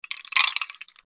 Ice Cubes In Drink 03
Ice_cubes_in_drink_03.mp3